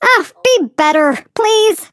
flea_kill_vo_04.ogg